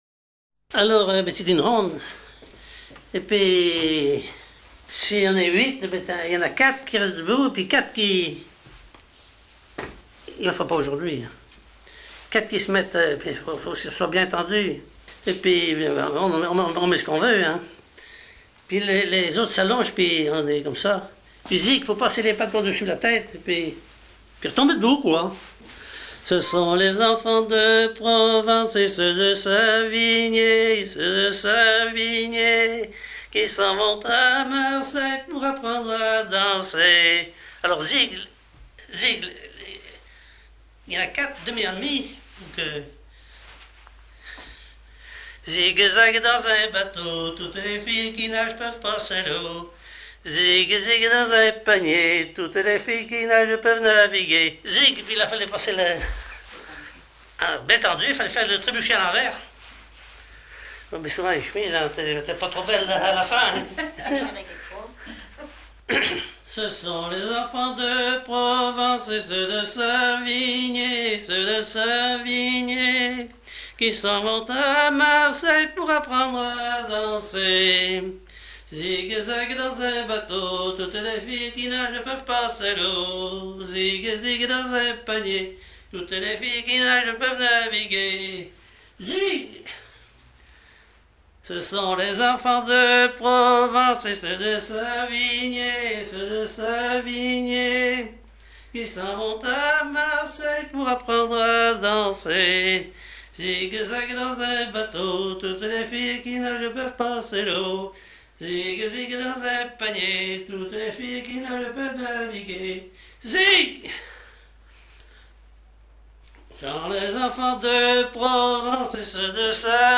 Couplets à danser
danse : ronde
Pièce musicale éditée